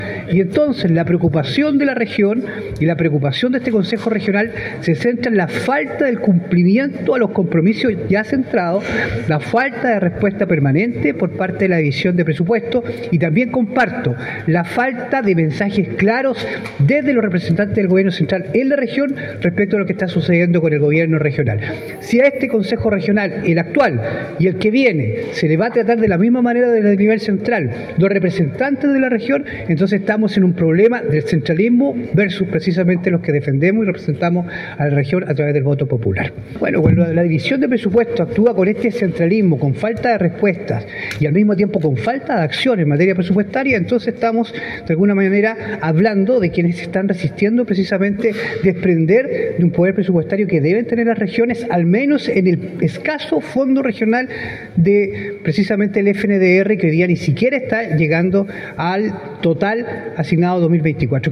El Consejero Reyes añadió que la falta de recursos pone en peligro la ejecución de importantes obras de infraestructura planificadas para diferentes comunas de Los Lagos, entre ellas proyectos de construcción y reparación de carreteras, implementación de infraestructura básica en sectores rurales, y mejoras en servicios públicos.